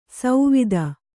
♪ sauvida